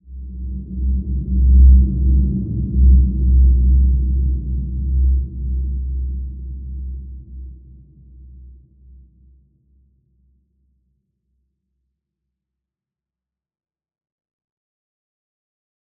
Large-Space-C2-f.wav